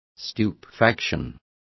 Complete with pronunciation of the translation of stupefaction.